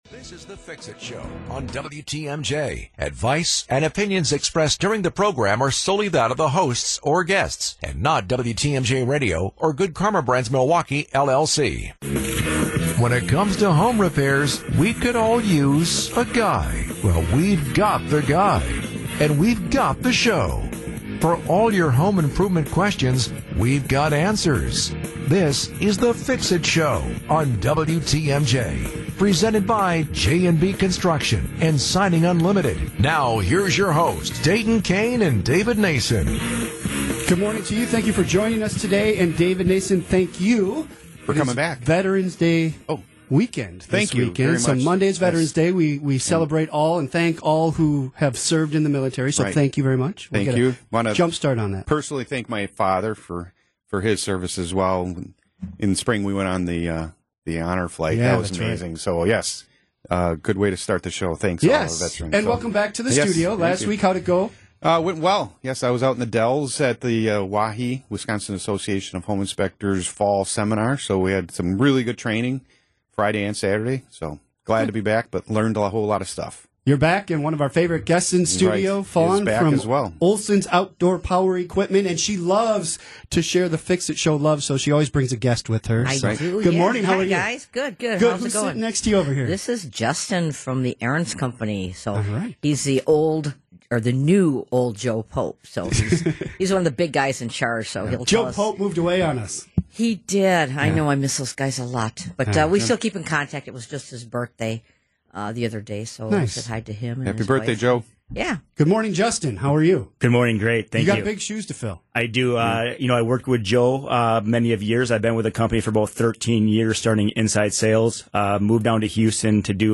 discuss home improvement projects and talk with special guests every Saturday morning.